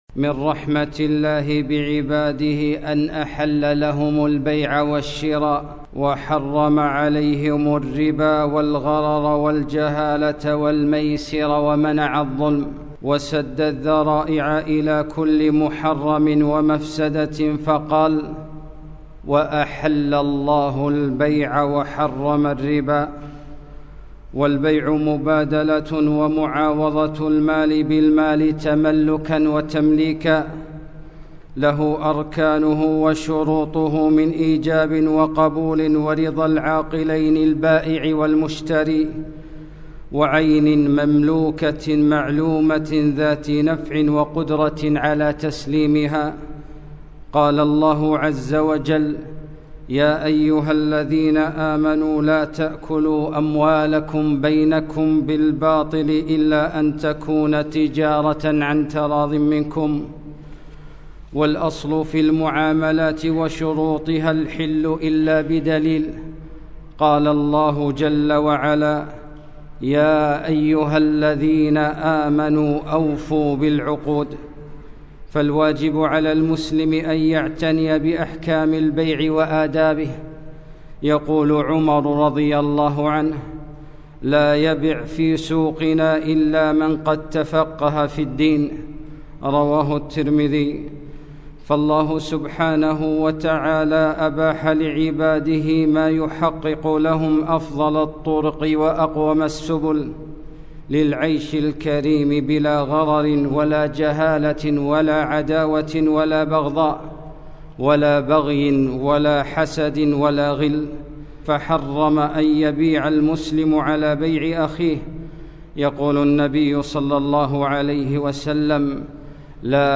خطبة - شروط البيع وأركانه وآدابه